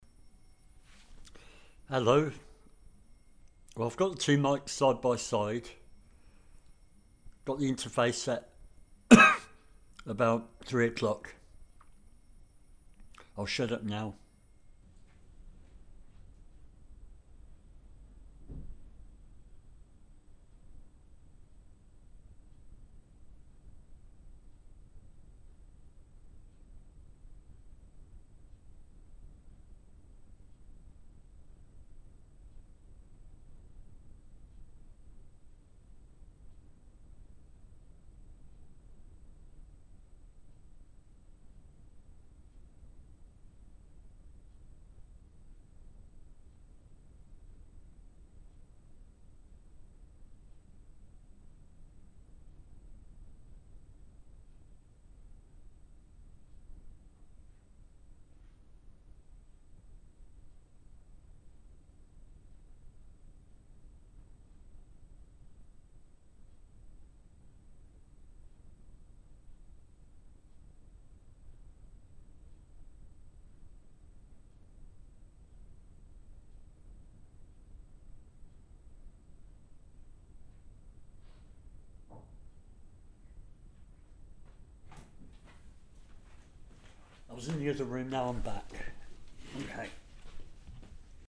You can see I am sure the greater noise on the left track.